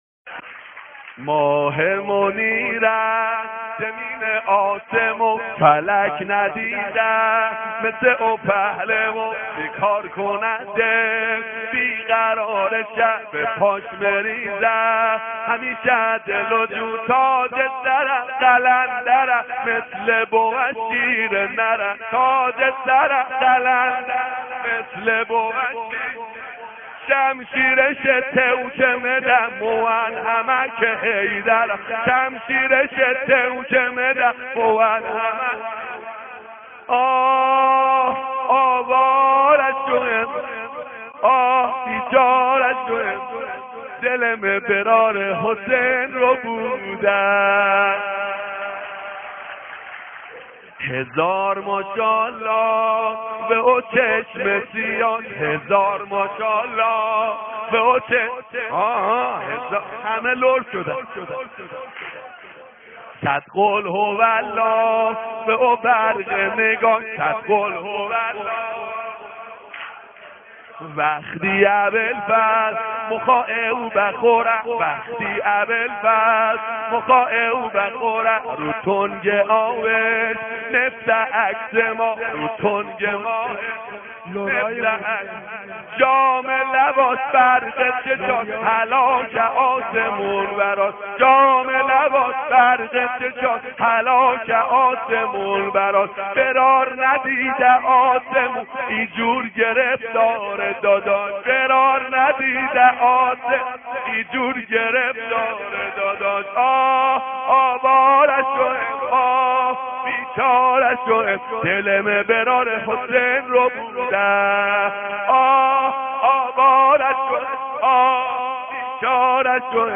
به گویش لری